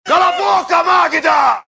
Bordão de Caco Antibes (Miguel Falabella) no programa Sai de Baixo.